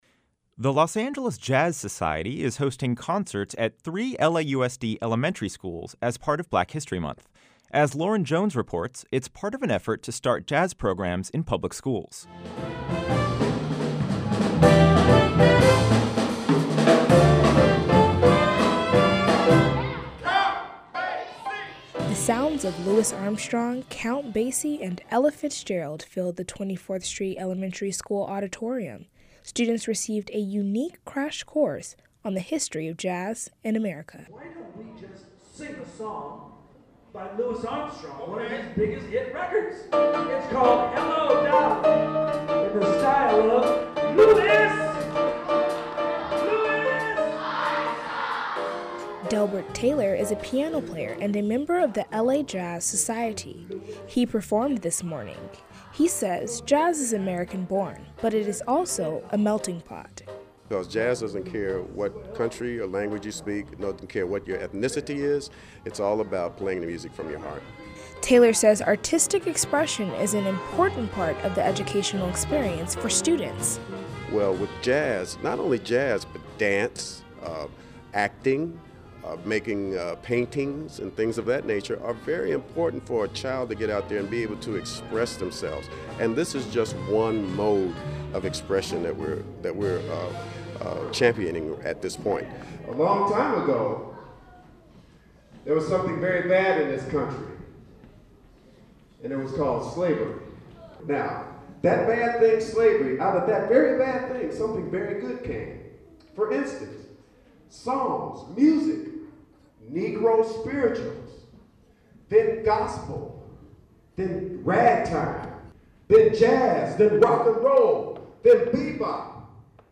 By the end of the performance, students were singing along, clapping, laughing and raising their hands to answer trivia questions.